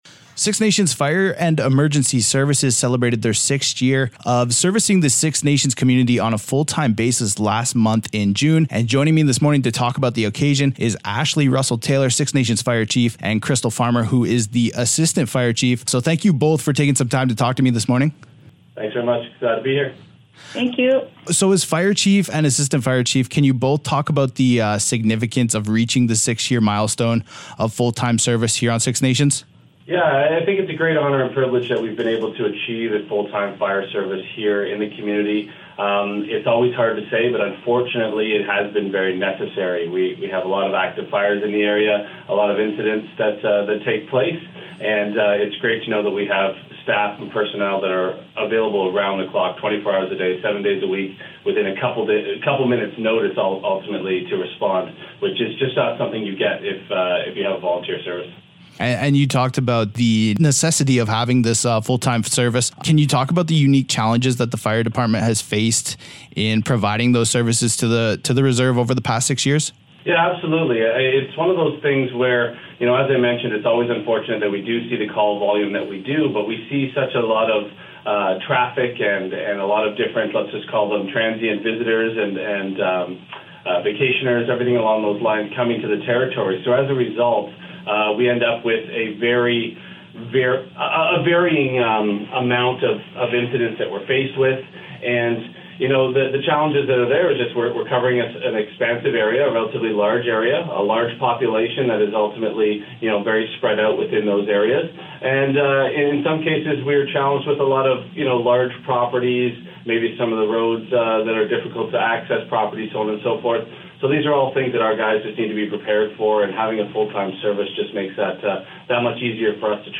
SN-Fire-Interview_01.mp3